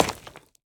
Minecraft Version Minecraft Version 1.21.5 Latest Release | Latest Snapshot 1.21.5 / assets / minecraft / sounds / block / pointed_dripstone / land2.ogg Compare With Compare With Latest Release | Latest Snapshot